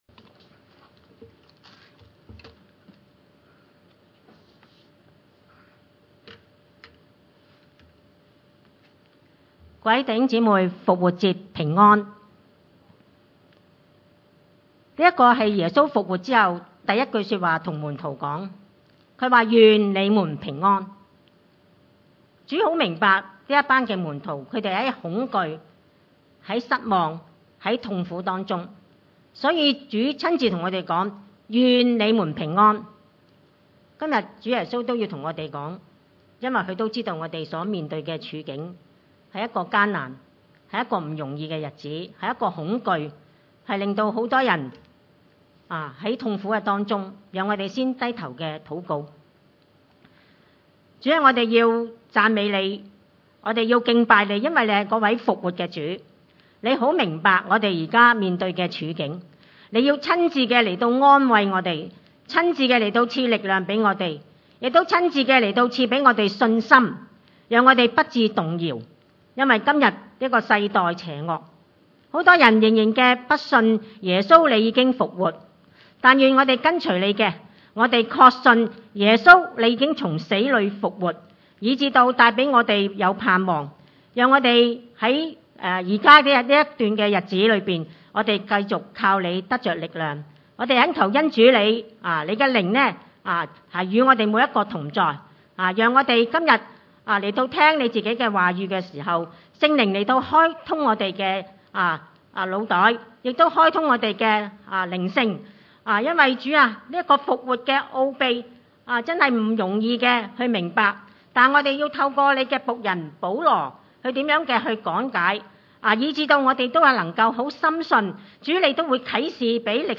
58 崇拜類別: 主日午堂崇拜 50 弟兄們，我告訴你們說，血肉之體，不能承受神 的國，必朽壞的，不能承受不朽壞的。